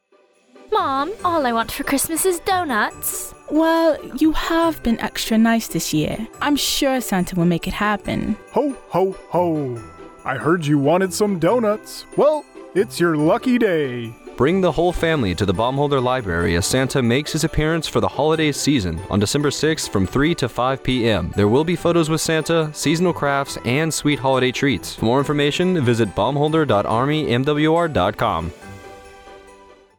Radio Spot - Donuts with Santa